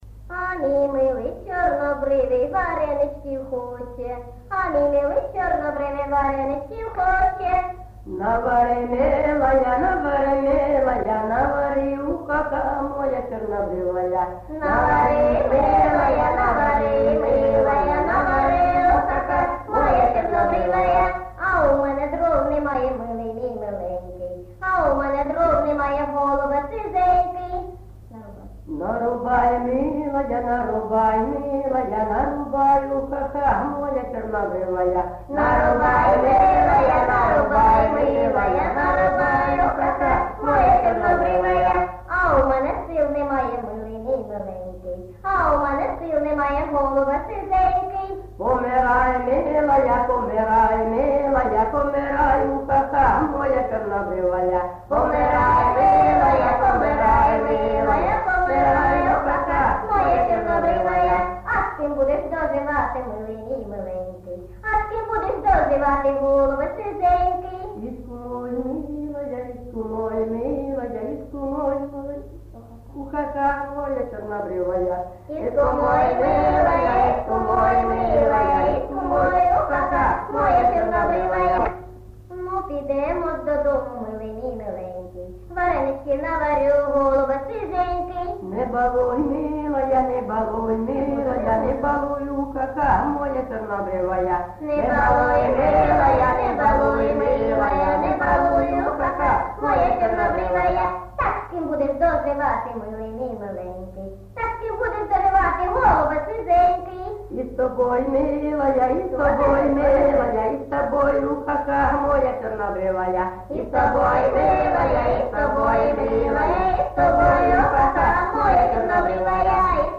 ЖанрЖартівливі